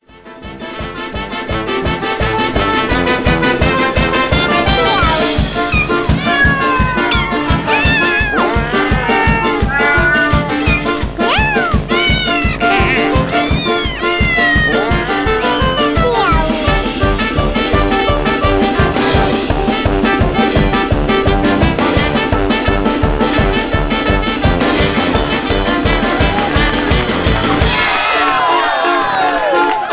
Aus dem Kindertanztheater